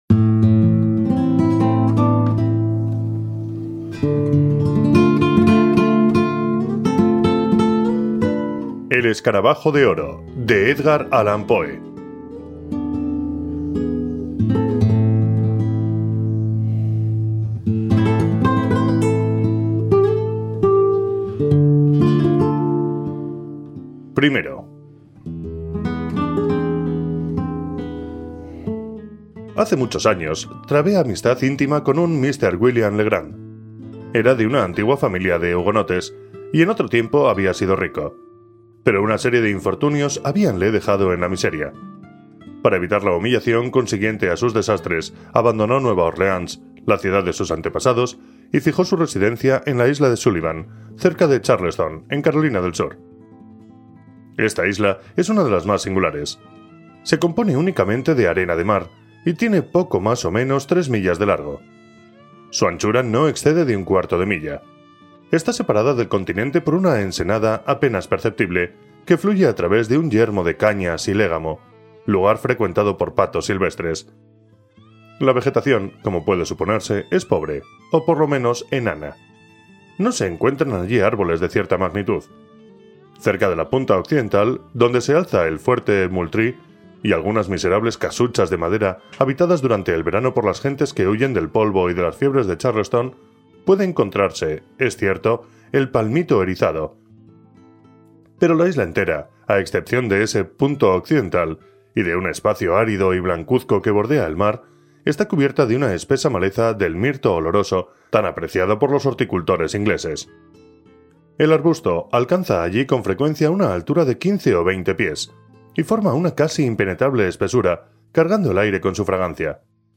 Audiolibro: El escarabajo de oro
Música: Juice connection & Pamauni (cc:by-sa)